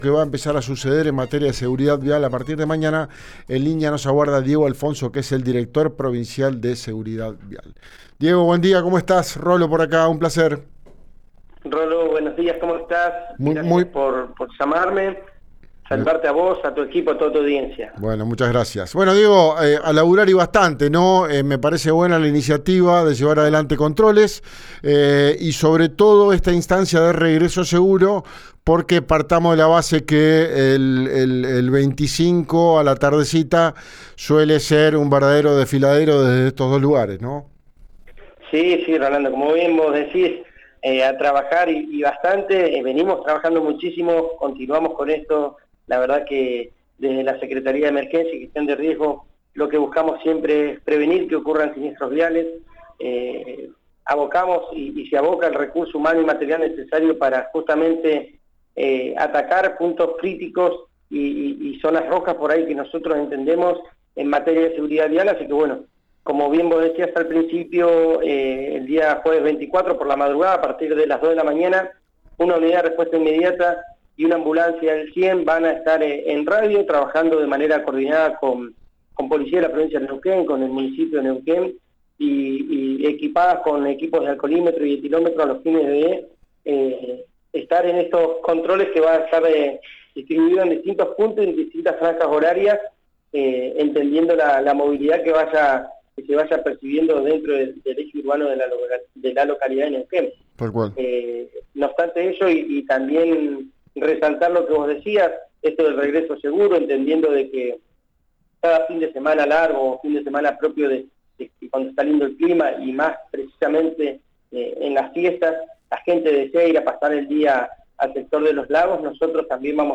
Escuchá al Director Provincial de Seguridad Vial, Diego Alfonso, en RÍO NEGRO RADIO: